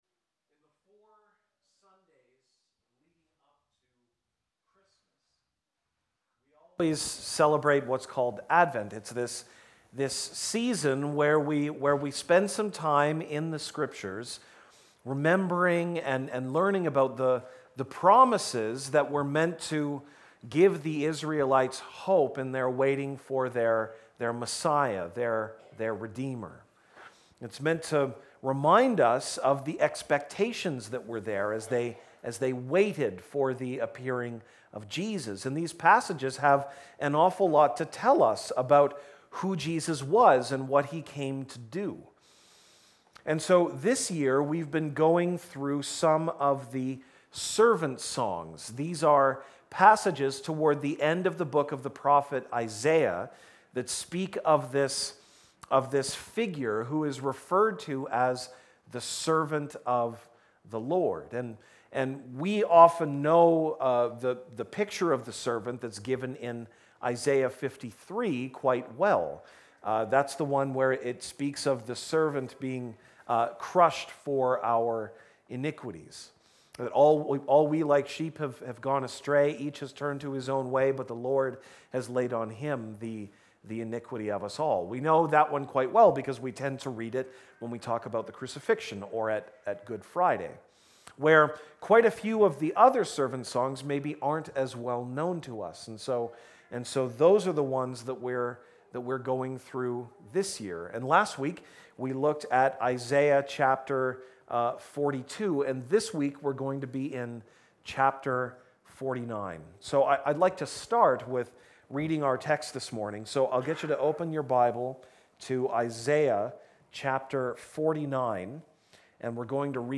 December 15, 2019 (Sunday Morning)